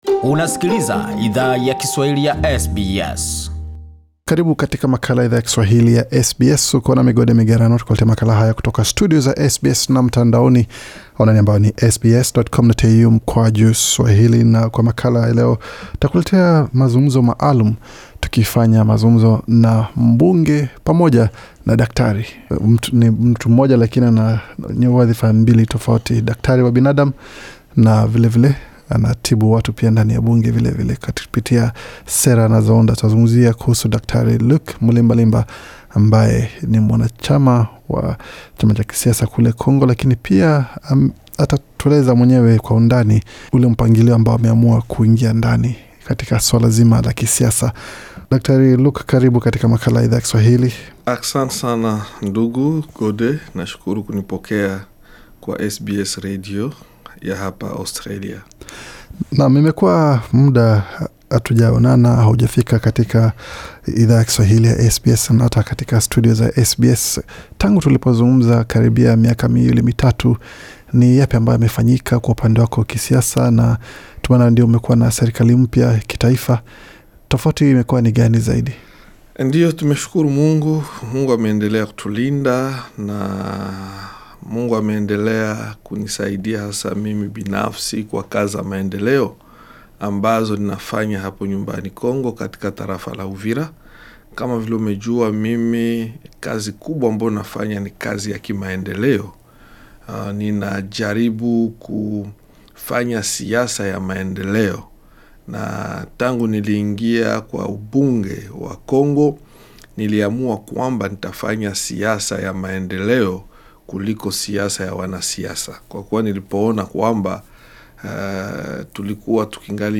Mh Dkt Luc Mulimbalimba ndani ya studio za redio ya SBS, mjini Sydney, Australia Source: SBS Swahili